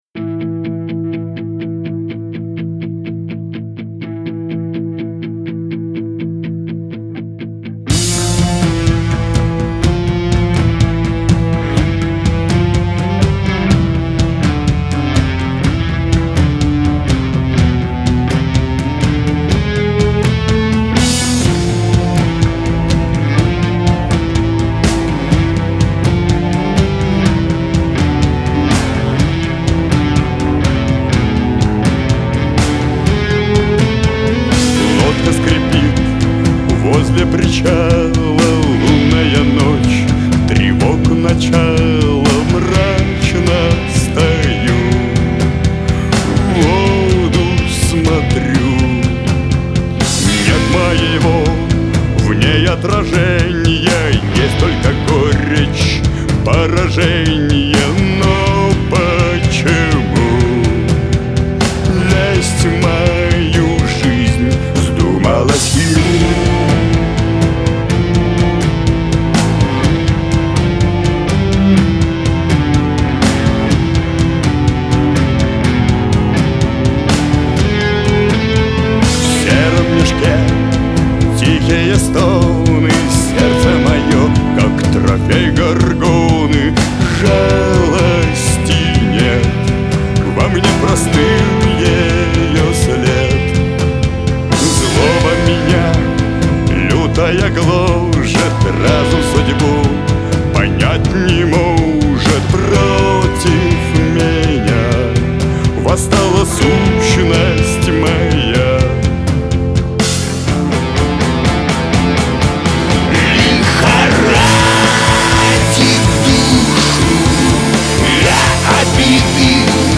Главная » Онлайн Музыка » Рок